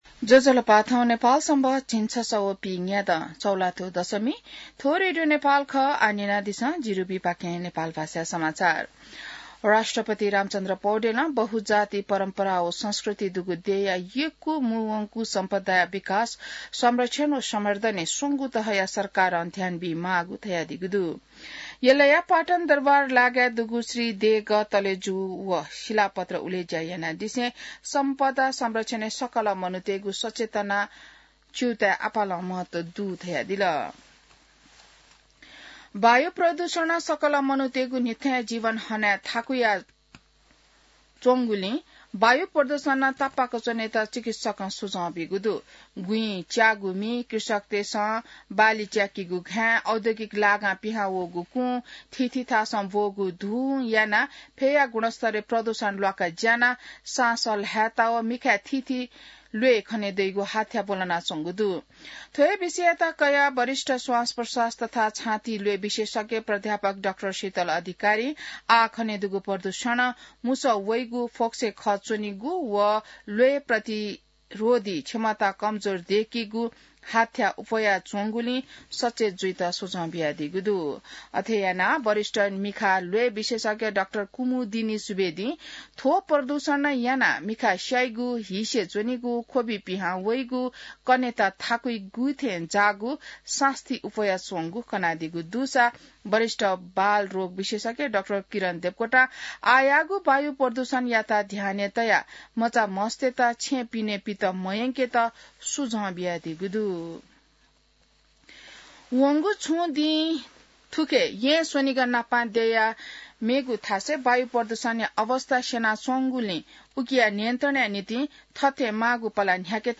An online outlet of Nepal's national radio broadcaster
नेपाल भाषामा समाचार : २५ चैत , २०८१